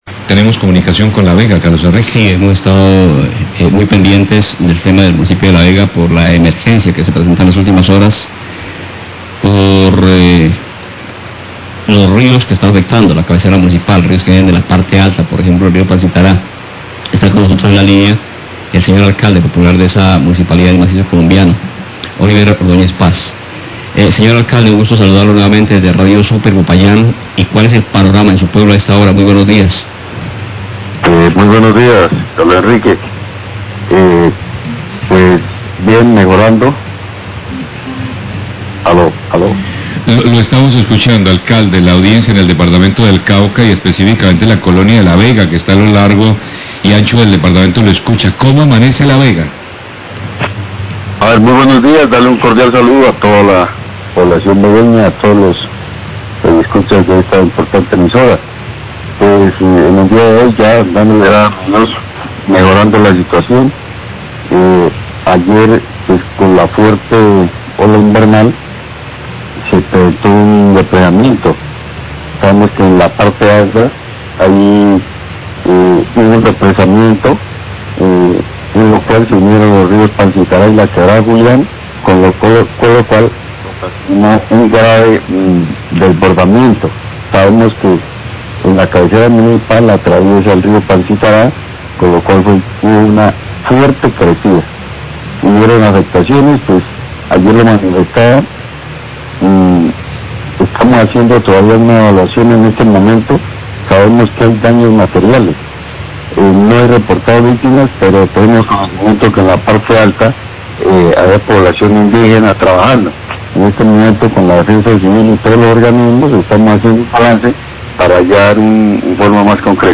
Radio
La situación en La Vega mejora tras la avalancha ocurrida en la tarde de ayer, no hay reporte de víctimas, el servicio de energía ya fue restablecido pero el servicio de agua aún no, se trabaja en eso. Declaraciones del Alcalde de la Vega, Carlos Oliver Ordoñez.